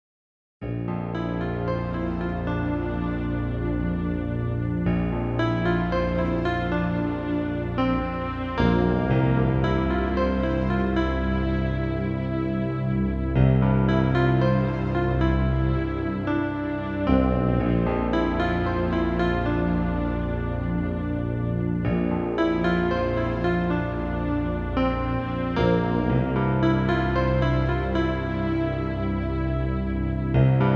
Home > Lullabies